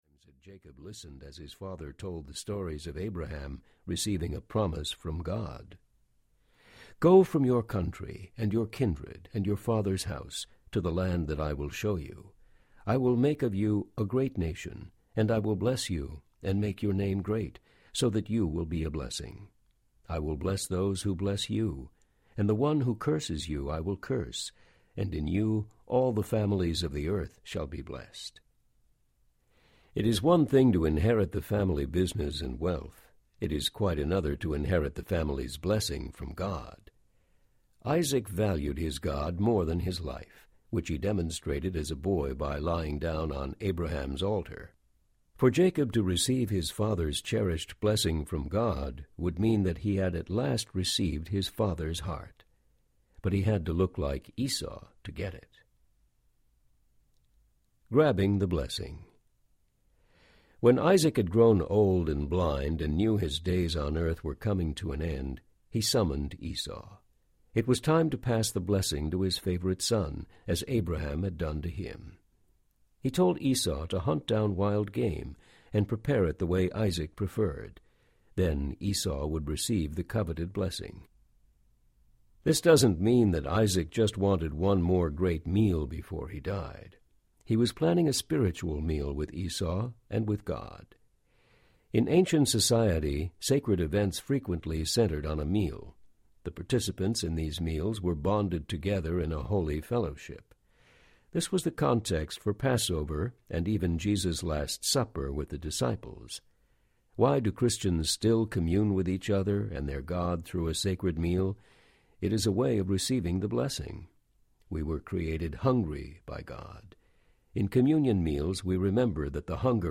Hustling God Audiobook
Narrator
6.91 Hrs. – Unabridged